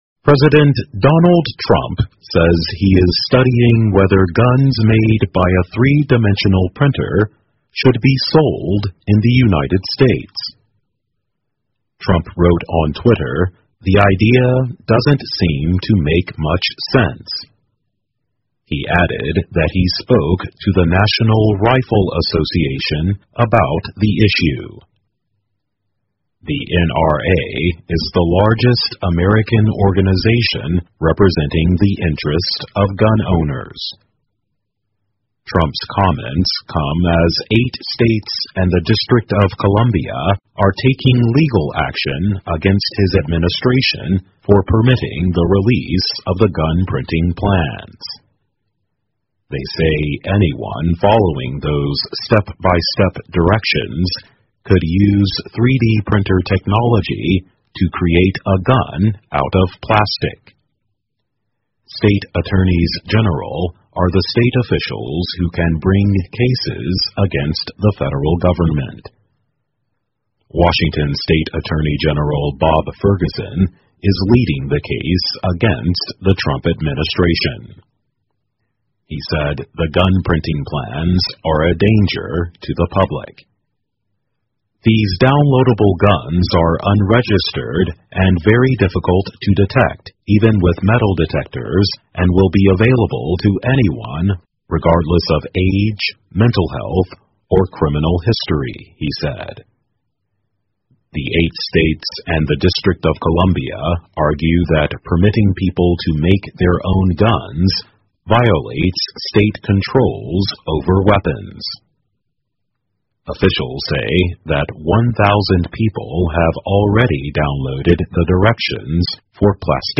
在线英语听力室 Trump Considering Sales of Guns Made by 3D Printers的听力文件下载,2018年慢速英语(八)月-在线英语听力室